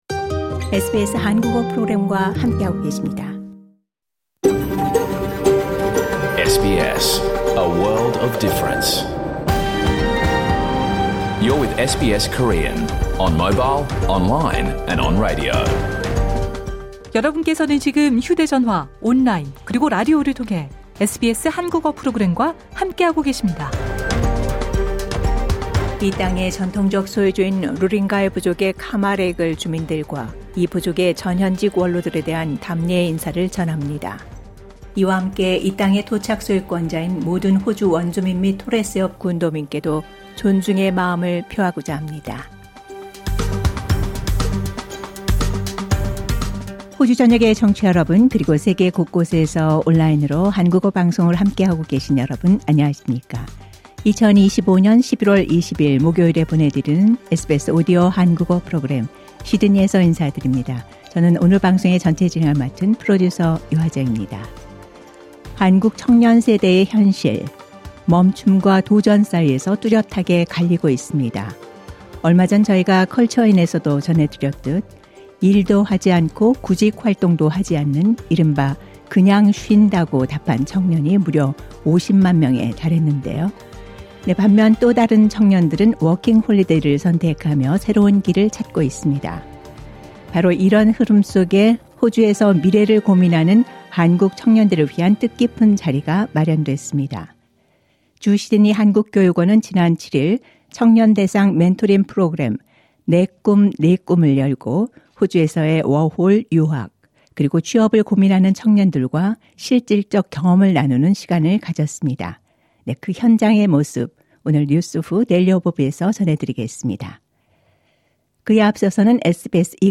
2025년 11월 20일 목요일에 방송된 SBS 한국어 프로그램 전체를 들으실 수 있습니다.